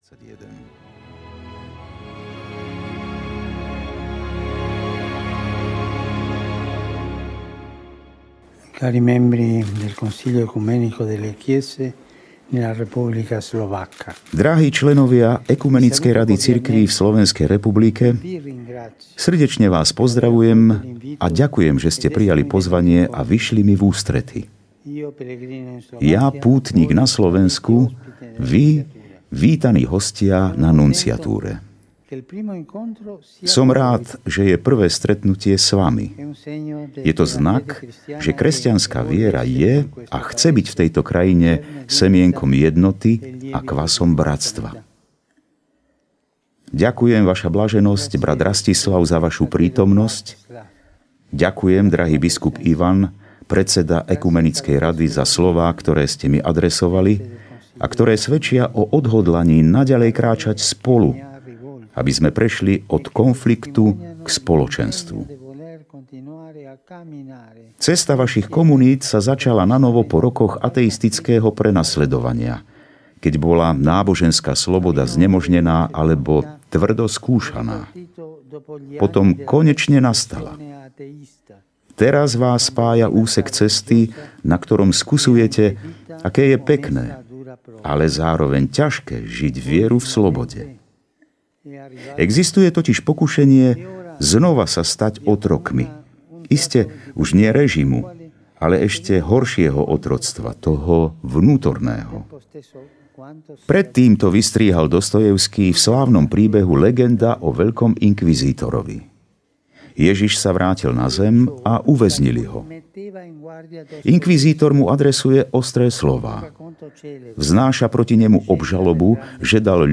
Príhovory pápeža Františka počas apoštolskej cesty na Slovensku audiokniha
Kompletné príhovory pápeža Františka počas apoštolskej cesty na Slovensku v dňoch 12. až 15. septembra 2021 nahovoril renomovaný herec Ján Gallovič.